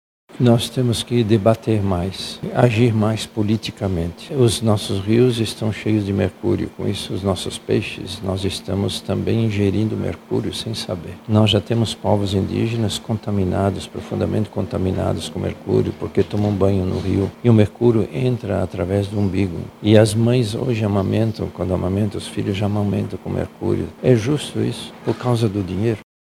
Este panorama vai contra ao que o Papa Francisco pregou durante o seu pontificado, o cuidado com a Casa Comum. O cardeal da Amazônia, Dom Leonardo Steiner, comenta sobre os impactos destas ações.